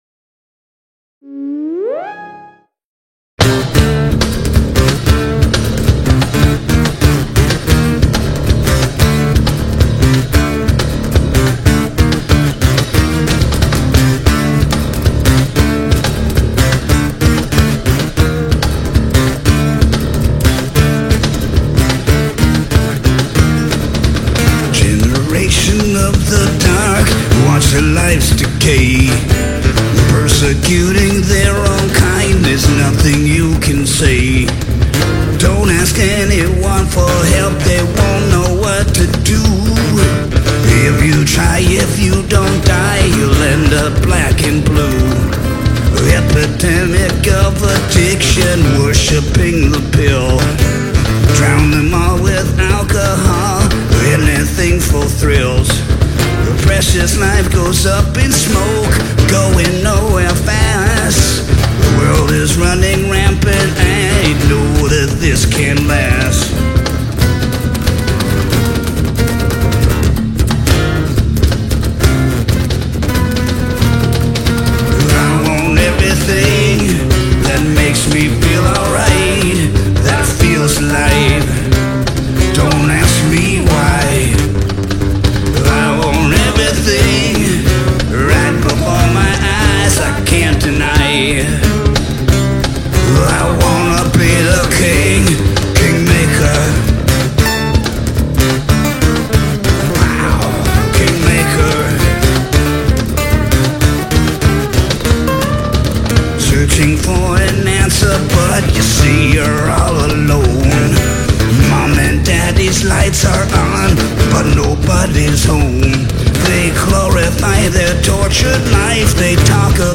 Heavy Metal, Hard Rock, Modern Metal